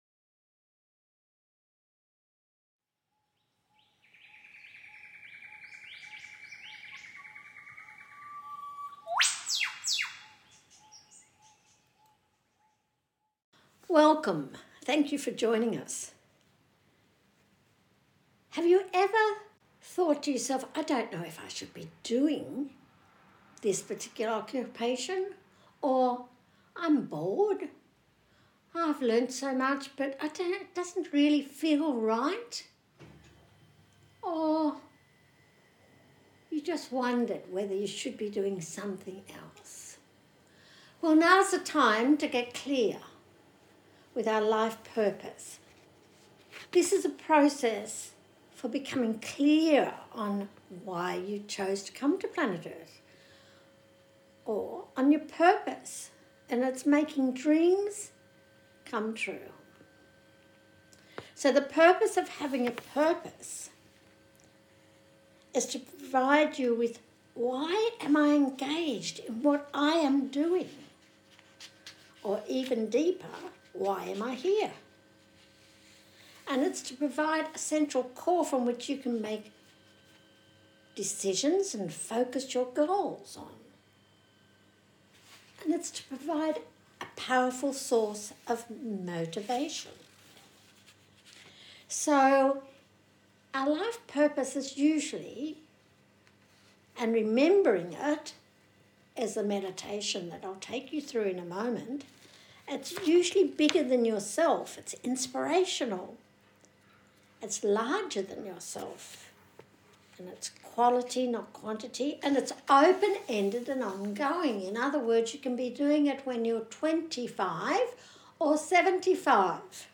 Life Purpose Meditation